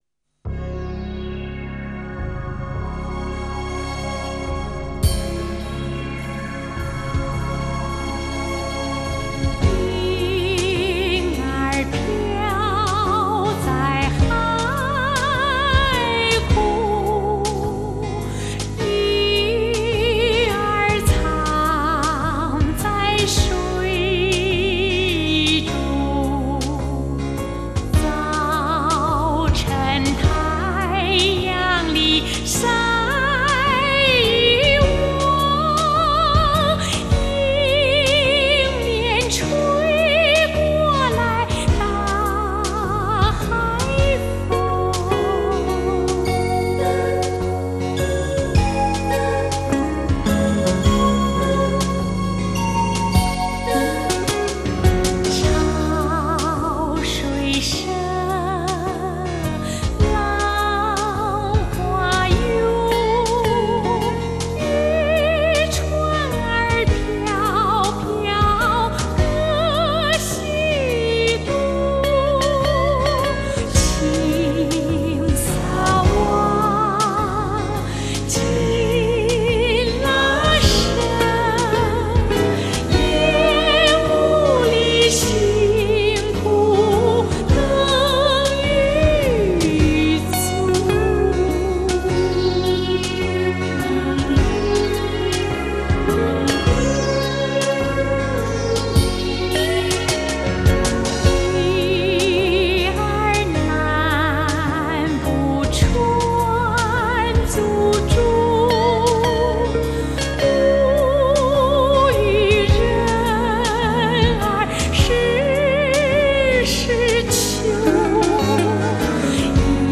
这歌音在旷远的情境中透露着压抑与哀愁。